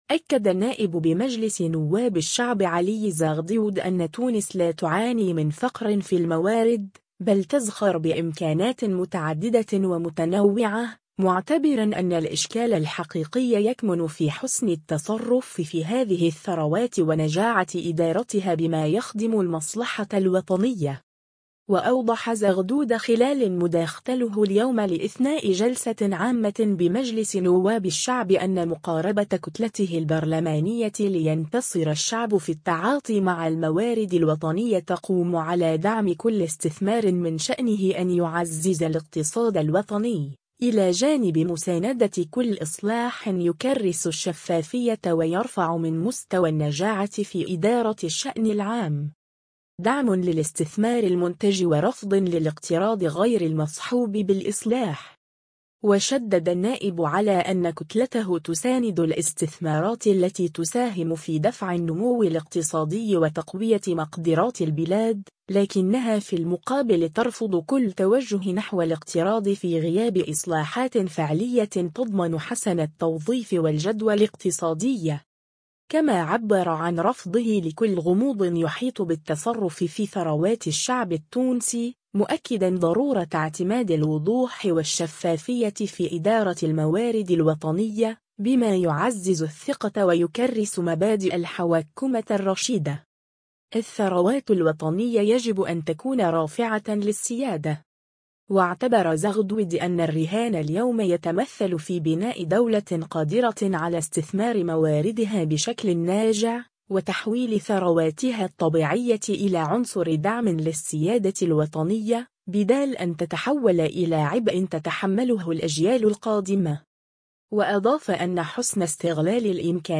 وأوضح زغدود خلال مداختله اليوم لإثناء جلسة عامة بمجلس نواب الشعب أن مقاربة كتلته البرلمانية “لينتصر الشعب” في التعاطي مع الموارد الوطنية تقوم على دعم كل استثمار من شأنه أن يعزز الاقتصاد الوطني، إلى جانب مساندة كل إصلاح يكرس الشفافية ويرفع من مستوى النجاعة في إدارة الشأن العام.